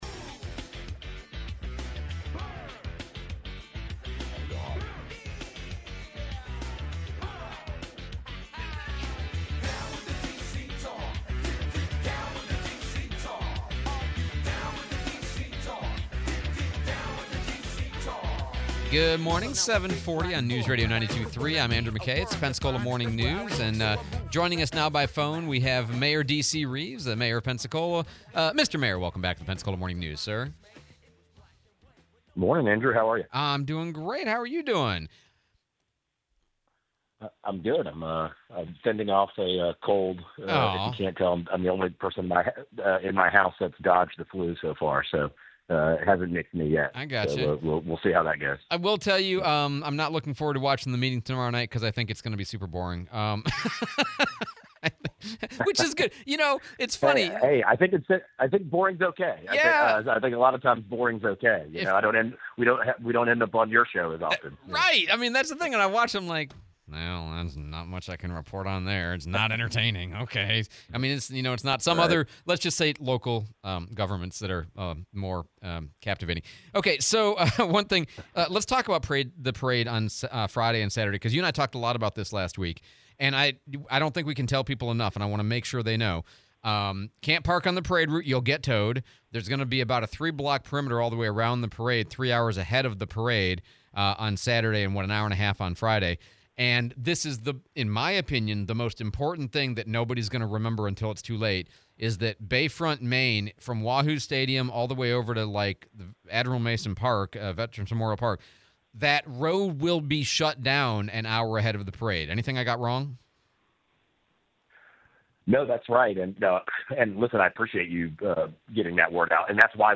02/26/25 7AM Interview with Mayor DC Reeves